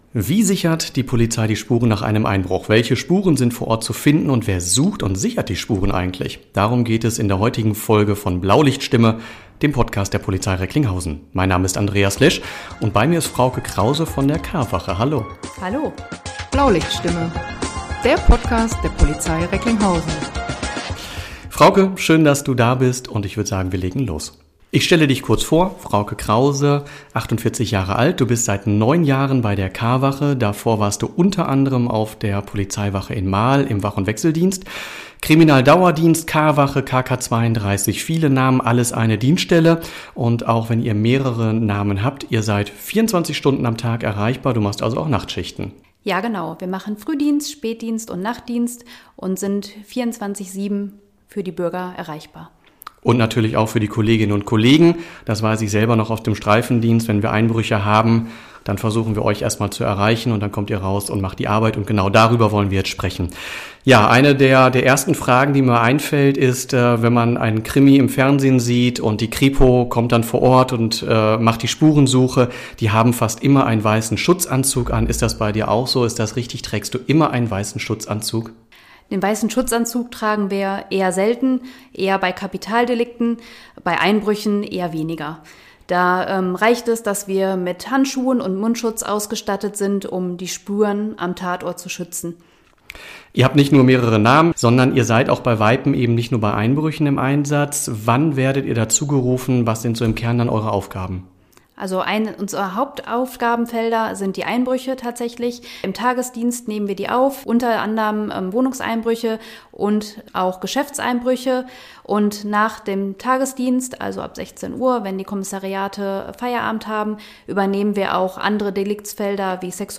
Die Kriminalhauptkommissarin erklärt worauf es bei der Spurensicherung an Tatorten, besonders nach einem Einbruch, ankommt.